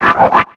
Cri de Chamallot dans Pokémon X et Y.